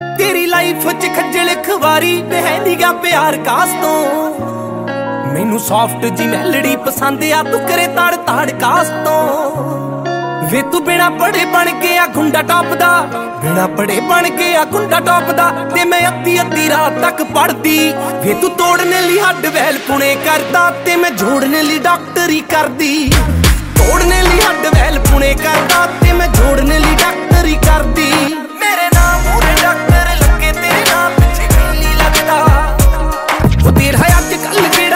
Punjabi Love Ringtones Romantic Ringtones